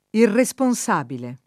[ irre S pon S# bile ]